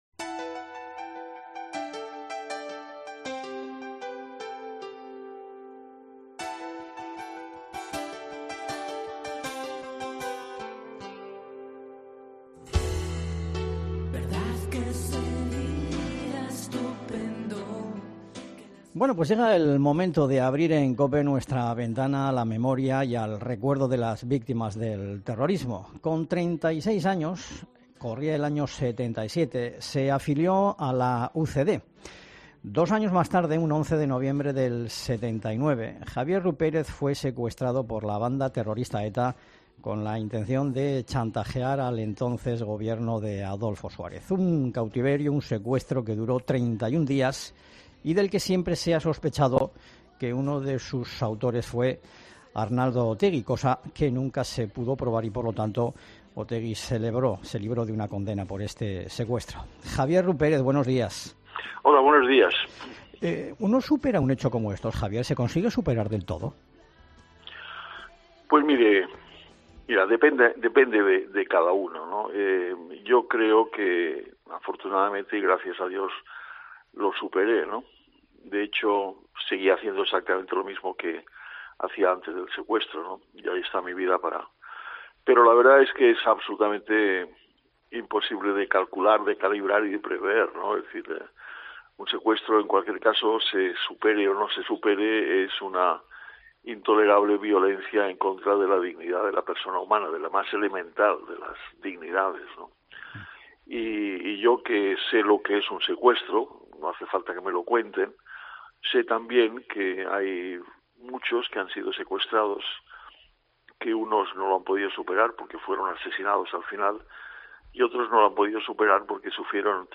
Javier Rupérez, secuestrado durante 31 dias por ETA. Entrevista en COPE Euskadi
Entrevista Javier Rupérez